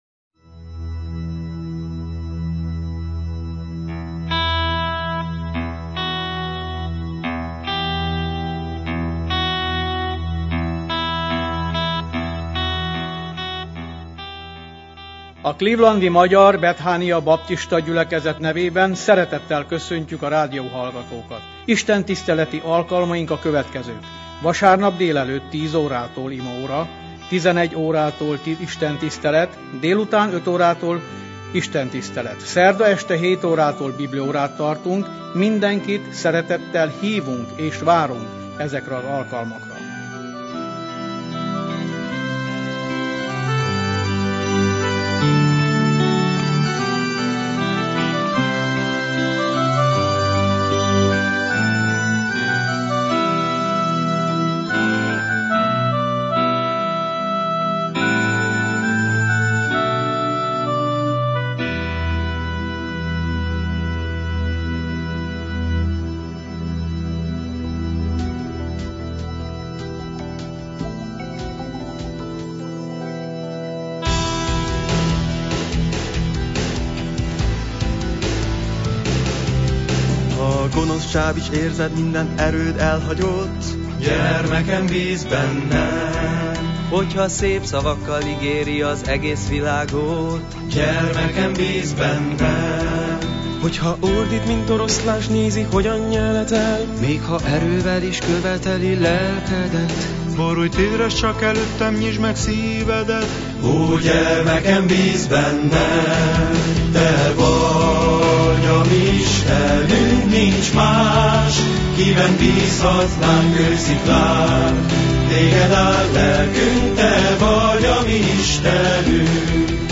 baptista prédikátor hirdeti az igét